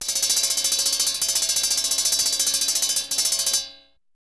Circus Bell Sound Effect Free Download
Circus Bell